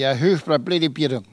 Elle crie pour appeler les oisons
Locution